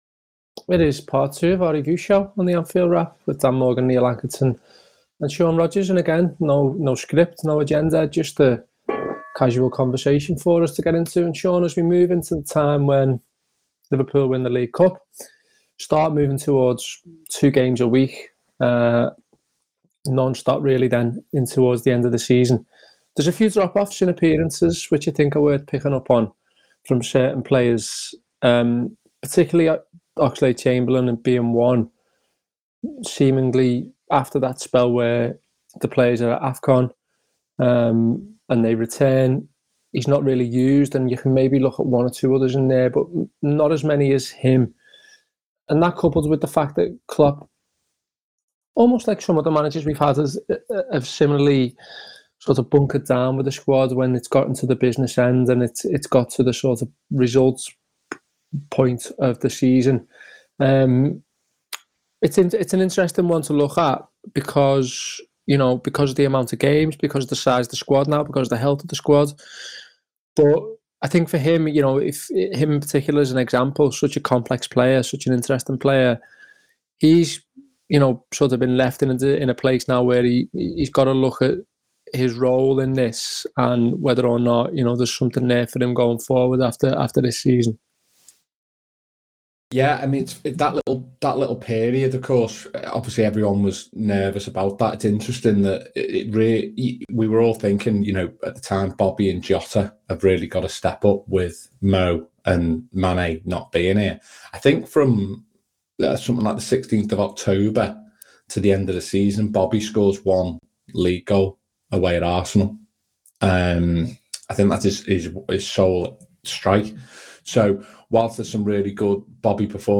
Below is a clip from the show – subscribe for more on Liverpool FC in 2021-22…